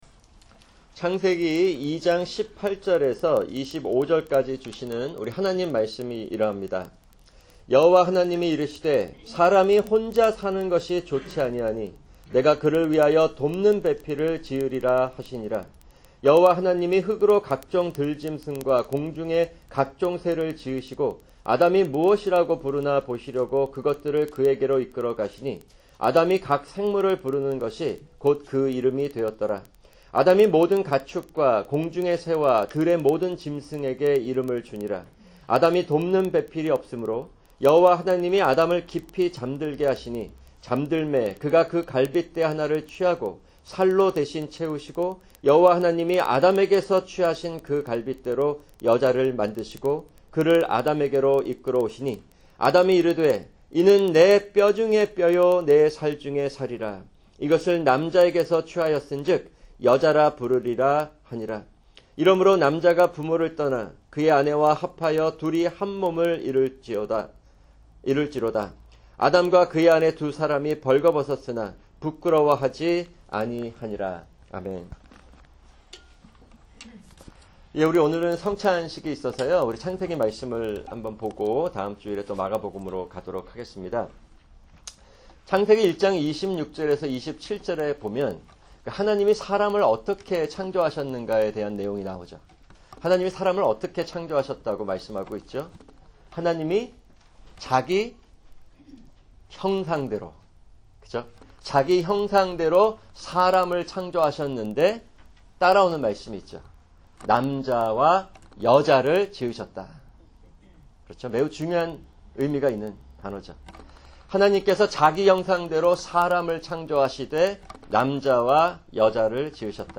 [주일 설교] 마가복음(4) 1:9-13